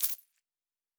Coins 05.wav